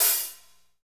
HAT P C L0QR.wav